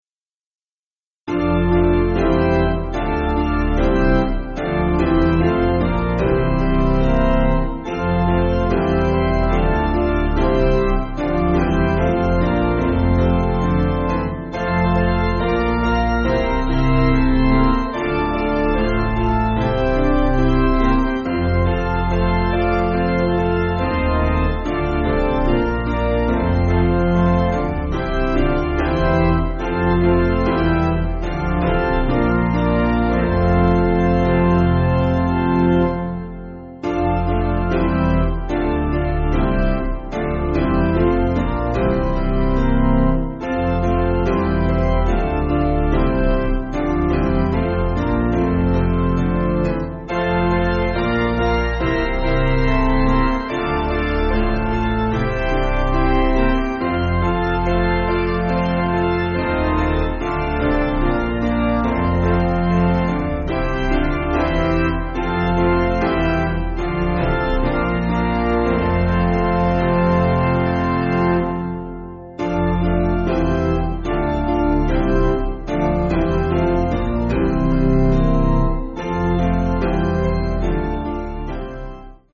Chinese folk-hymn
Basic Piano & Organ